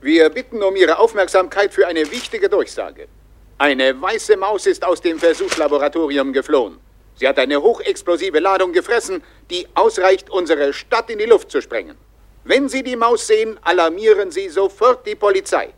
Radiostimme